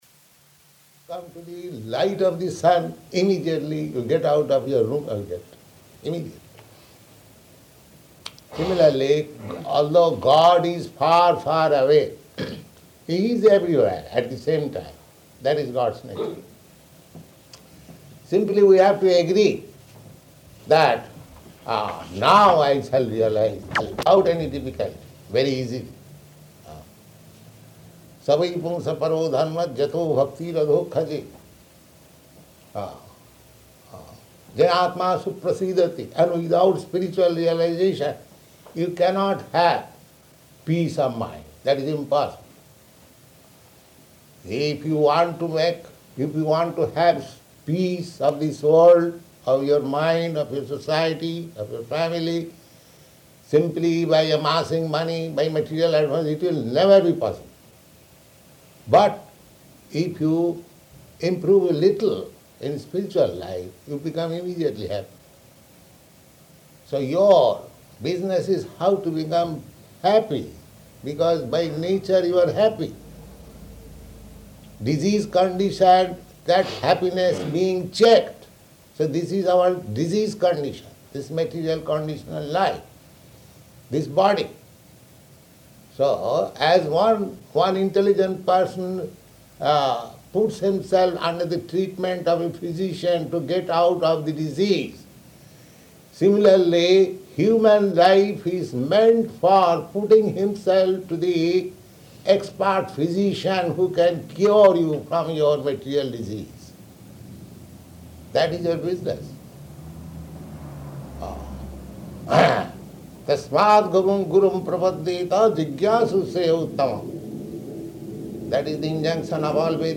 Lecture [partially recorded]